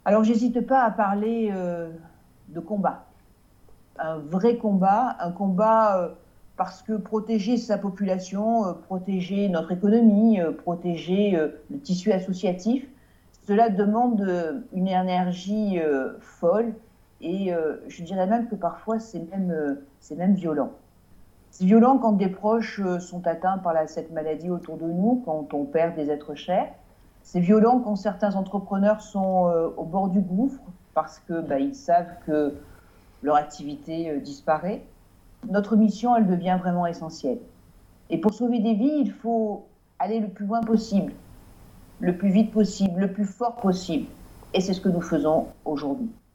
Vœux à la presse de Martine Vassal : -Nous avons réalisé des promesses des années 70-